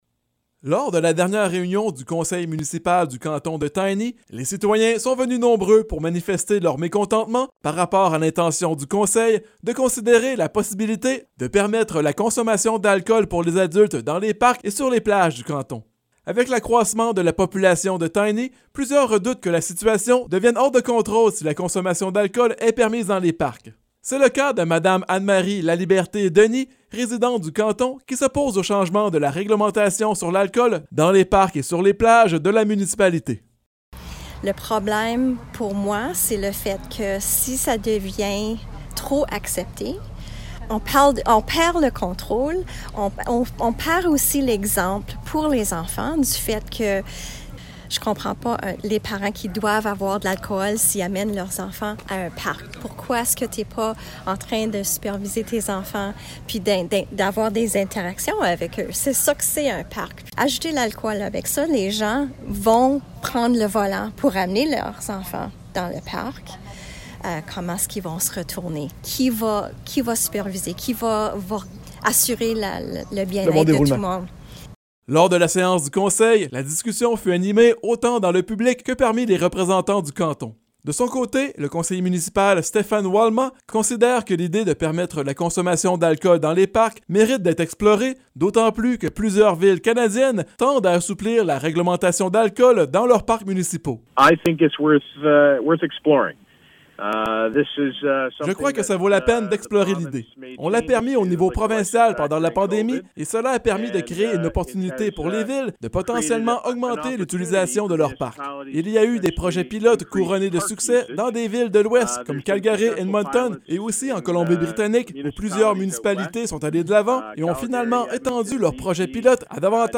Reportage-sur-lacool-dans-les-parc-a-Tiny.mp3